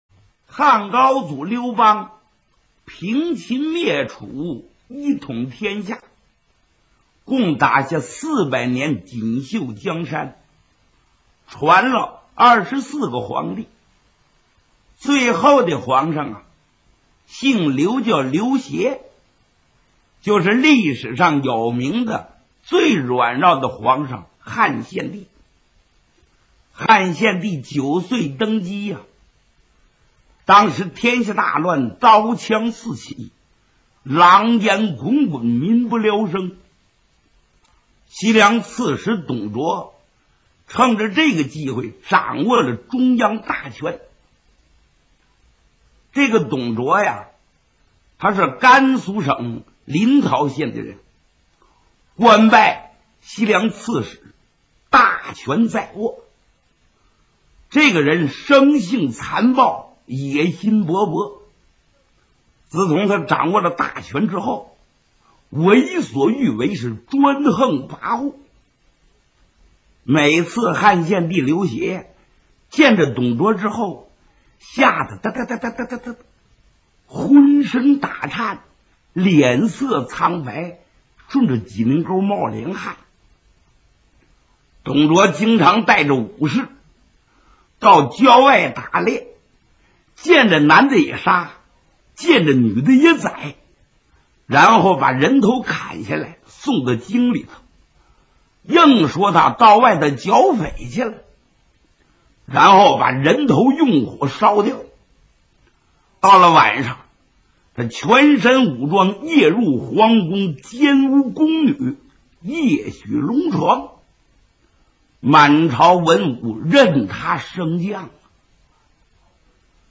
[14/12/2010][应助]【评书连播】《三国演义》（全112回）（播讲 单田芳）[32K MP3][115网盘]